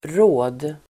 Uttal: [rå:d]